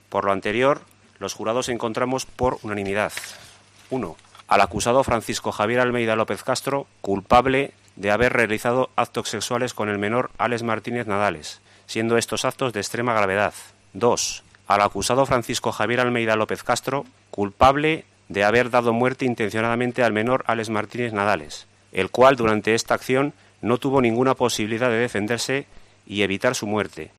El portavoz ha dado lectura el criterio del jurado.